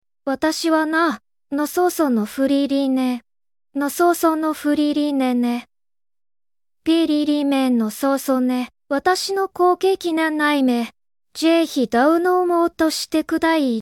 语音：日语